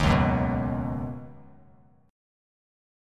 C#7sus2sus4 chord